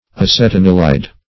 acetanilide - definition of acetanilide - synonyms, pronunciation, spelling from Free Dictionary
acetanilide \ac`et*an"i*lide\ ([a^]s`[e^]t*[a^]n"[i^]*l[i^]d),